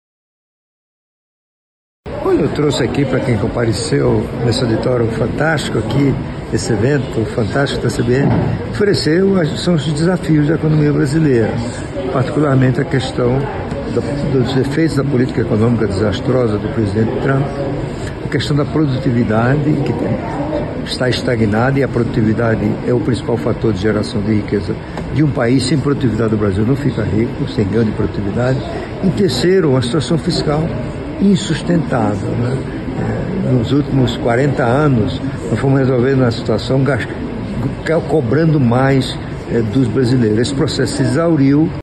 A abertura do Ciclo de Palestras CBN em 2025 contou com o economista e ex-ministro da Fazenda, Maílson da Nóbrega.
SONORA-PALESTRA-MAILSON-01-JC.mp3